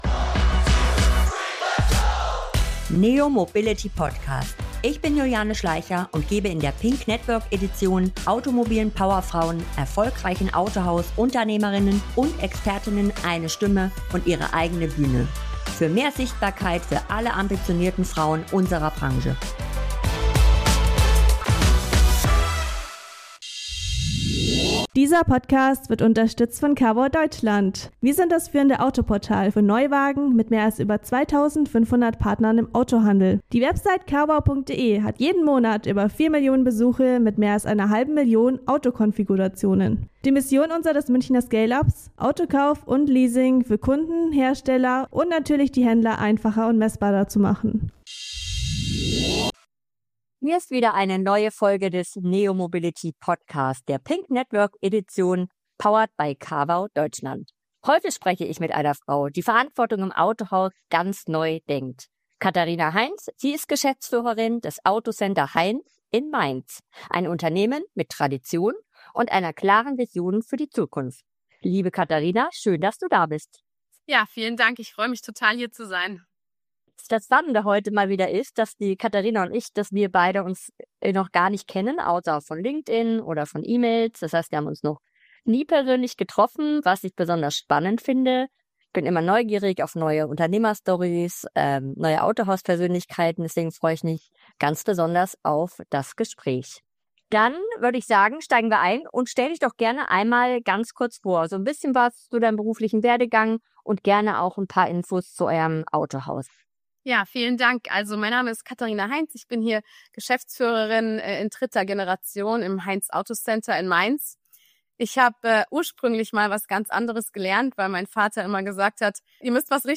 Ein inspirierendes Gespräch über Mut, Verantwortung und modernes Unternehmertum.